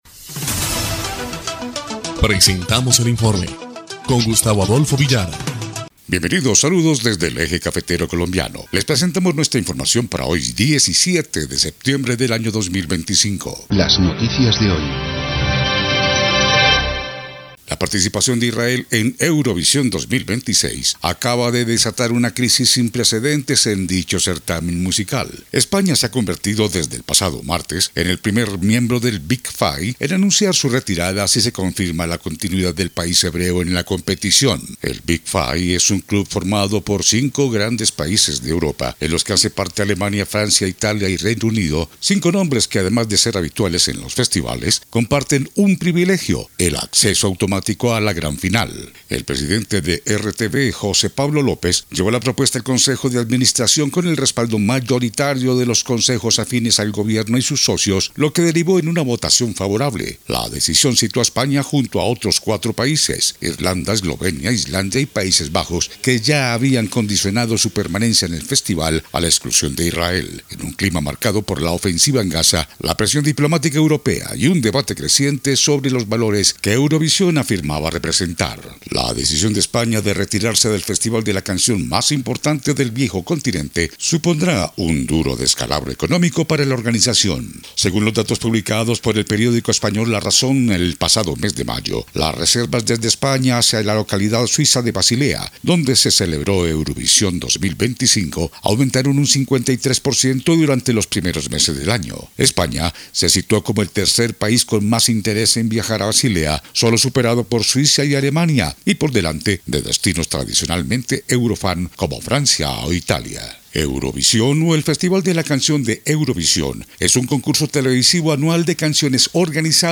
EL INFORME 1° Clip de Noticias del 17 de septiembre de 2025